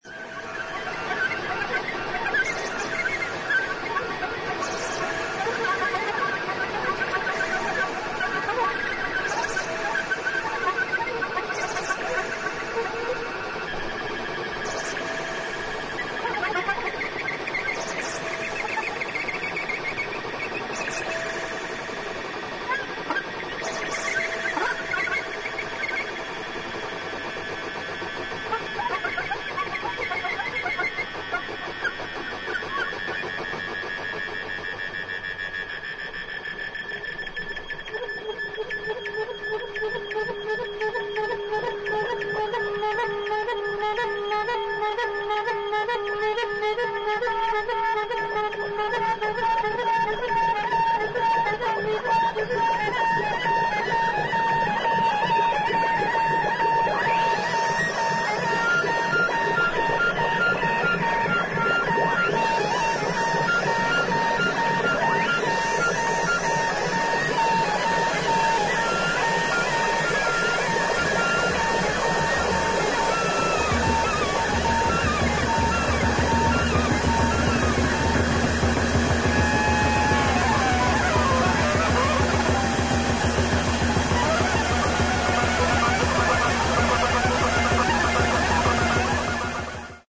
1977年12月にイタリア/ピストイアで行ったライヴを収録したCDアルバム